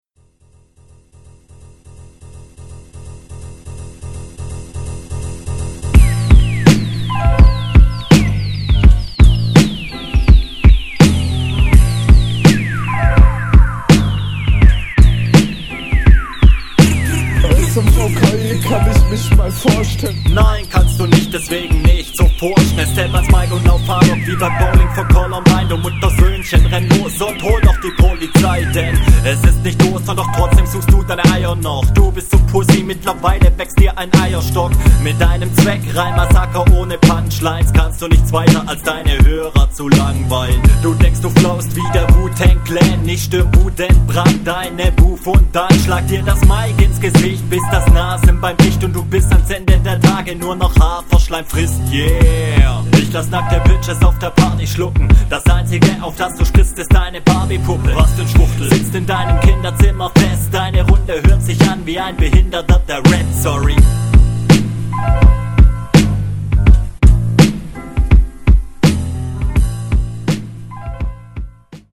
Stimme etwas leise, fällt mir schwerer dich zu verstehen.
Schön Hops genommen, Schöner smoother Einstieg.